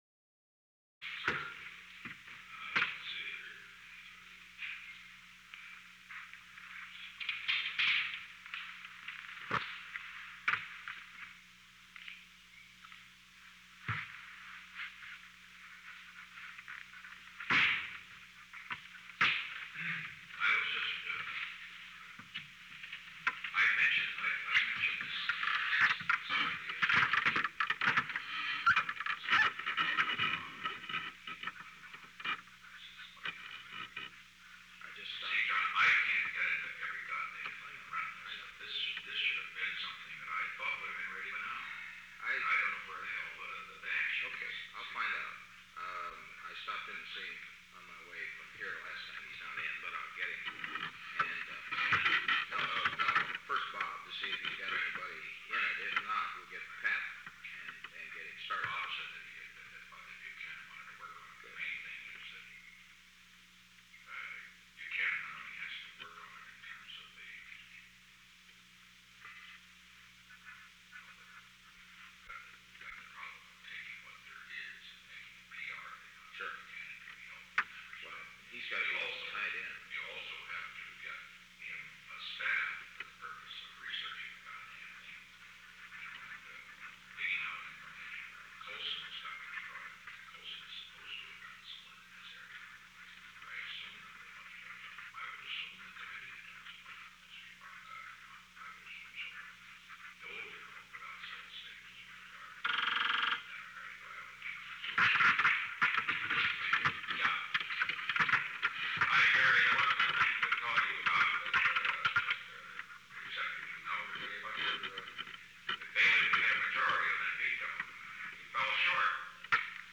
Conversation No. 893-16 Date: April 11, 1973 Time: 12:34 pm - 1:20 pm Location: Oval Office The President met with John D. Ehrlichman. Watergate -White House counterattack -H. R. (“Bob”) Haldeman -Availability -Patrick J. Buchanan -Public relations -Need for staff -Research -John W. Dean, III -Charles W. Colson -Robert J. Dole The President talked with Gerald R. Ford between 12:35 pm and 12:39 pm.